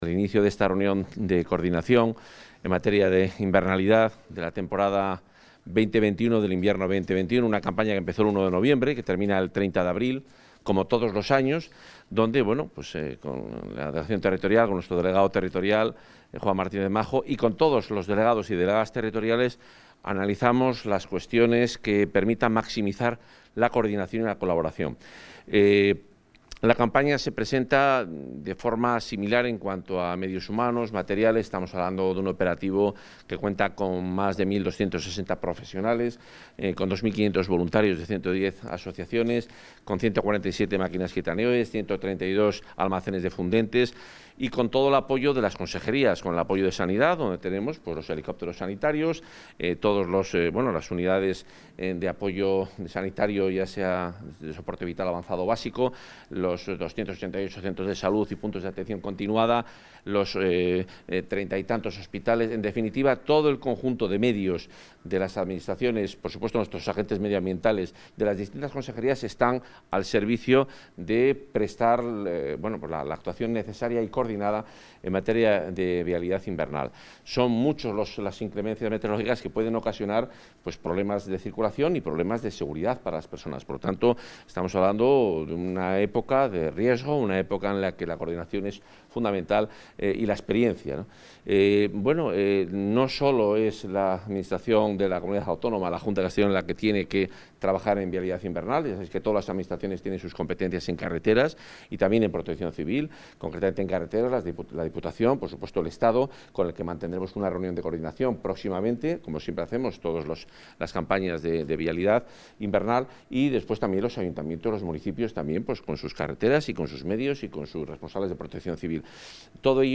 Intervención del consejero de Fomento y Medio Ambiente.